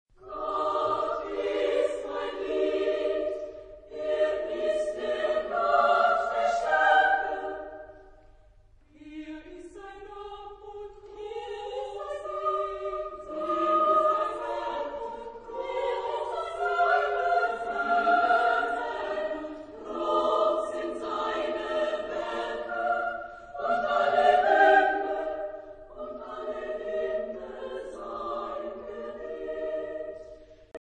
Genre-Style-Forme : Motet ; Sacré
Type de choeur : SSAA  (4 voix égales de femmes )
Tonalité : ré majeur
Réf. discographique : 7. Deutscher Chorwettbewerb 2006 Kiel